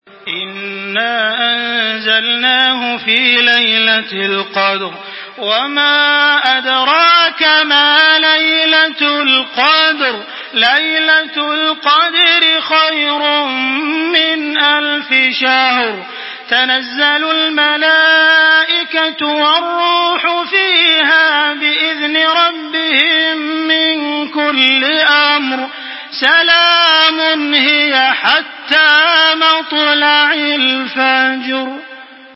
سورة القدر MP3 بصوت تراويح الحرم المكي 1425 برواية حفص عن عاصم، استمع وحمّل التلاوة كاملة بصيغة MP3 عبر روابط مباشرة وسريعة على الجوال، مع إمكانية التحميل بجودات متعددة.
تحميل سورة القدر بصوت تراويح الحرم المكي 1425
مرتل